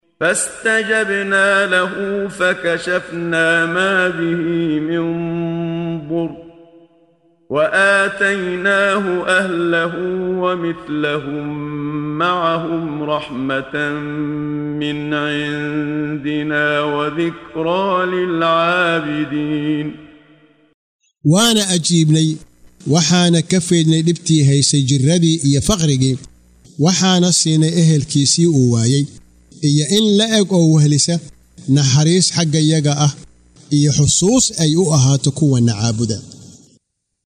Waa Akhrin Codeed Af Soomaali ah ee Macaanida Suuradda Al-Anbiyấa ( Nabiyada ) oo u kala Qaybsan Aayado ahaan ayna la Socoto Akhrinta Qaariga Sheekh Muxammad Siddiiq Al-Manshaawi.